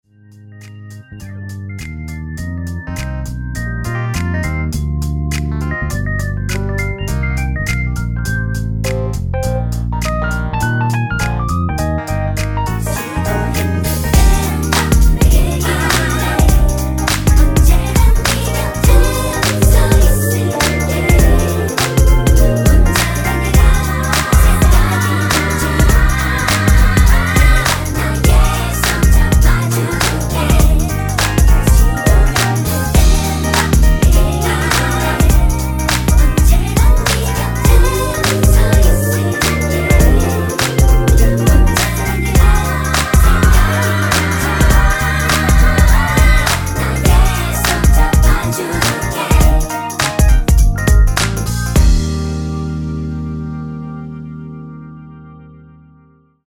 MR은 2번만 하고 노래 하기 편하게 엔딩을 만들었습니다.(본문의 가사와 미리듣기 확인)
원키에서(+3)올린 코러스 포함된 MR입니다.
Eb
앞부분30초, 뒷부분30초씩 편집해서 올려 드리고 있습니다.
중간에 음이 끈어지고 다시 나오는 이유는